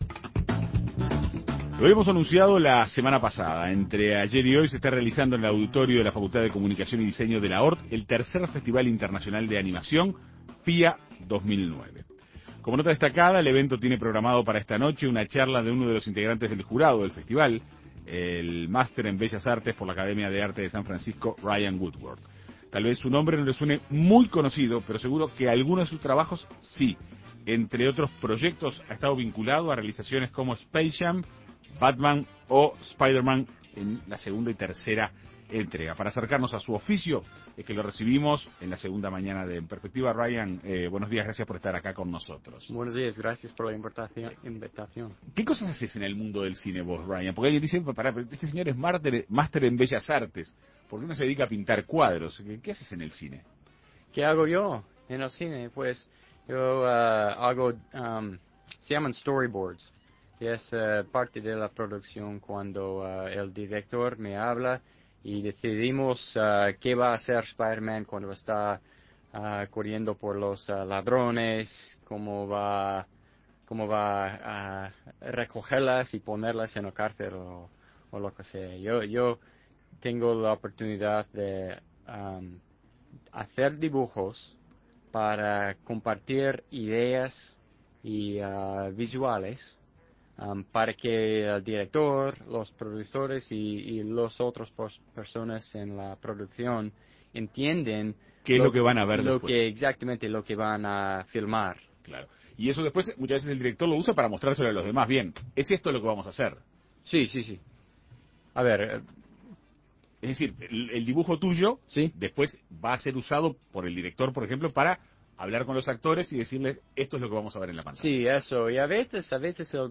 En Perspectiva Segunda Mañana dialogó con él para conocer de cerca su oficio.